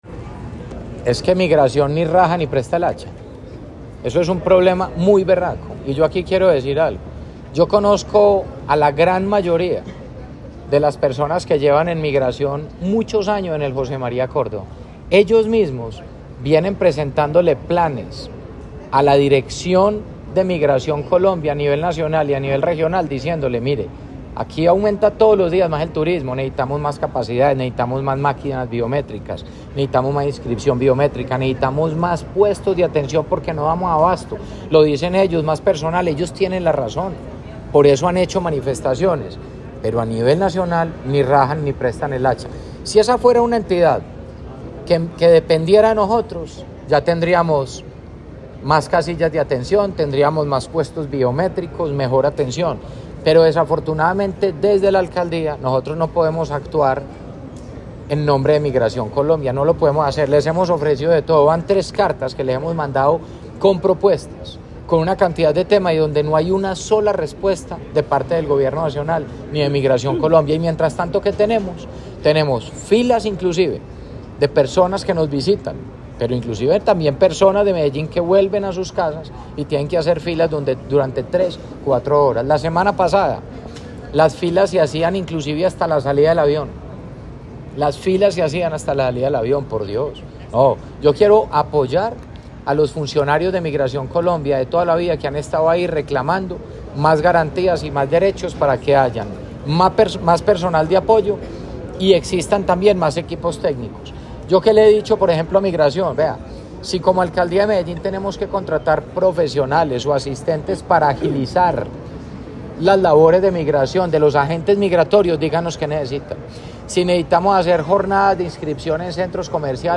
Declaraciones alcalde de Medellín, Federico Gutiérrez
Declaraciones-alcalde-de-Medellin-Federico-Gutierrez-4.mp3